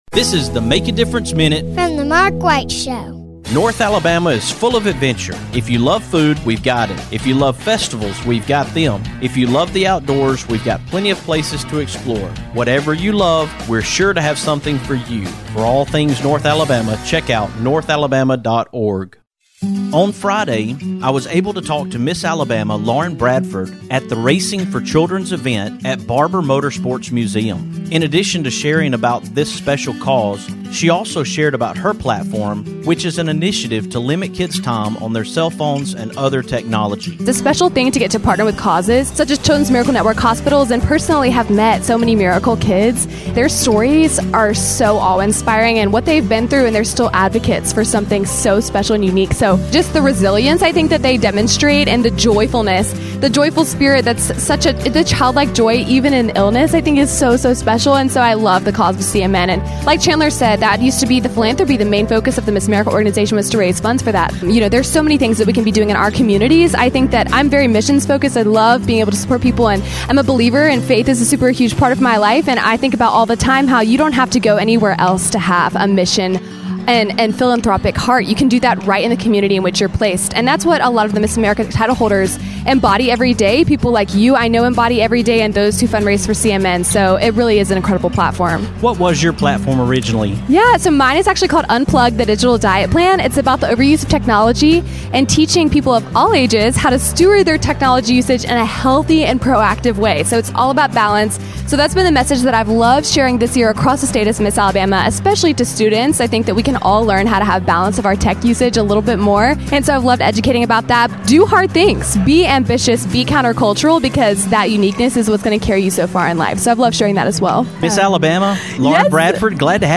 coming to you from Barber Motorsports Museum at the Racing for Children's fundraiser